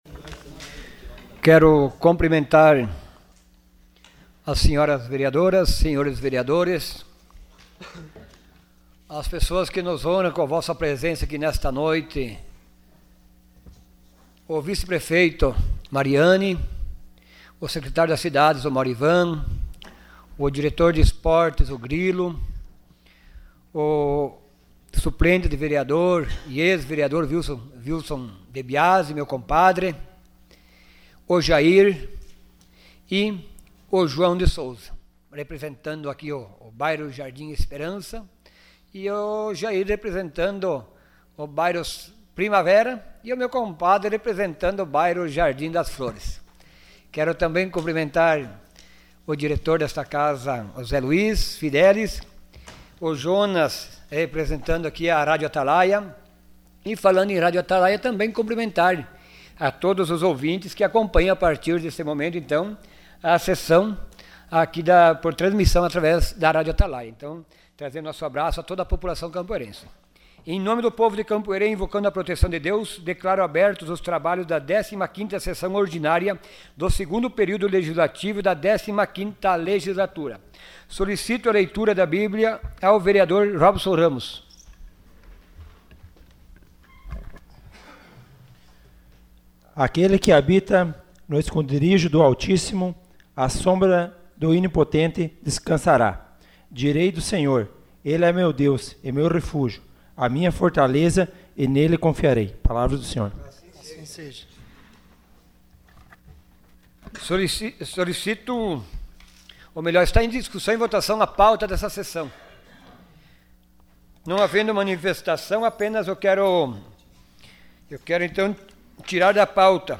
Sessão Ordinária dia 09 de abril de 2018.